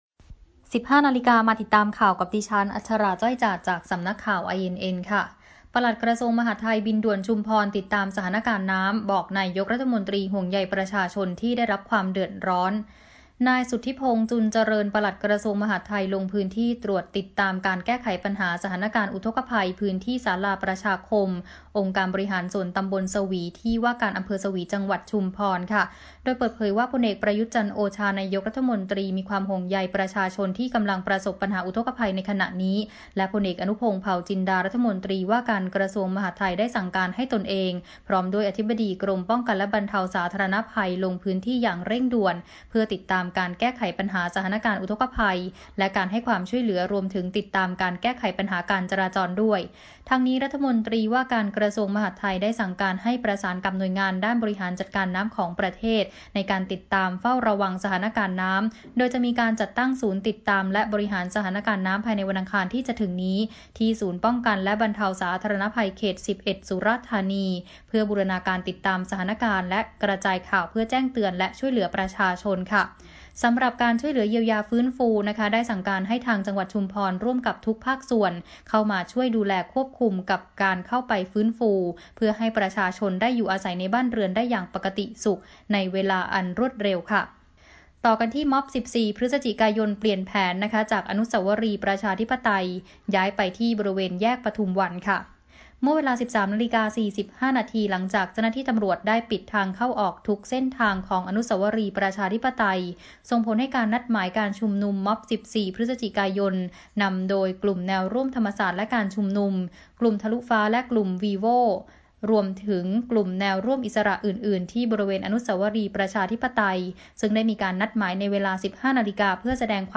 ข่าวต้นชั่วโมง 15.00 น.